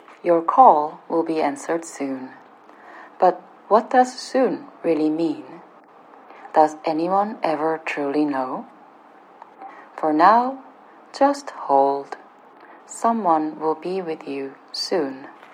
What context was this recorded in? Operator_filtered13.mp3